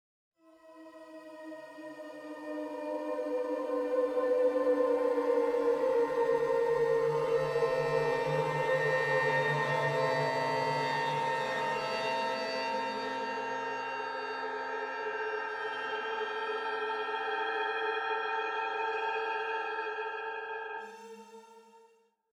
Nella pratica, significa che se premo un singolo tasto sentirò il suono modificarsi man mano che aumento il valore di Modulation.
In questo esempio sto premendo un C3 (il Do della terza ottava della tastiera) mentre modifico dal sequencer il valore di Modulation, dal minimo al massimo. Come puoi sentire, a ogni modifica passo da un layer all’altro in modo continuo, senza stacchi.